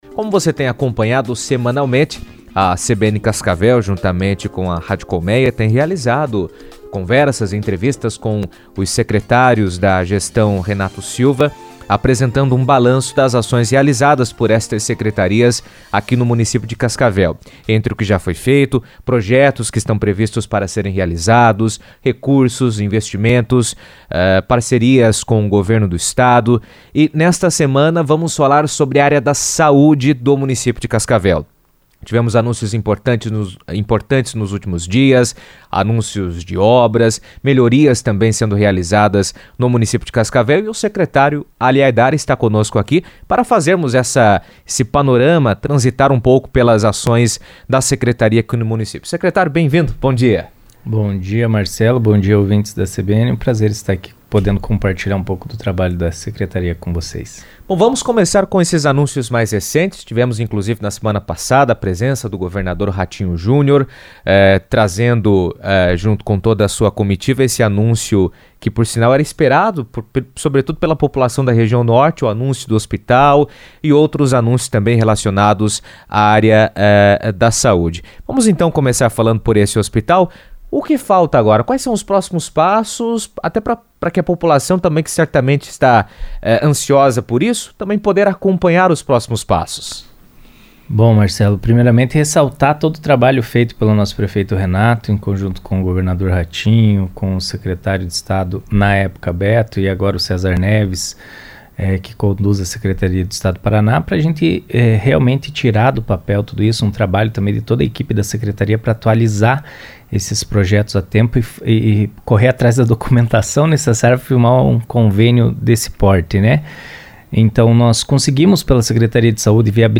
A Secretaria de Saúde de Cascavel tem ampliado investimentos em infraestrutura, com reformas e modernização de unidades, além da aquisição de equipamentos para qualificar o atendimento. Entre as iniciativas, estão a ampliação de serviços, fortalecimento da atenção básica e o desenvolvimento de projetos voltados à redução de filas e à melhoria no acesso da população. Em entrevista à CBN, Ali Haidar apresentou um balanço das ações e destacou as principais frentes de trabalho da pasta.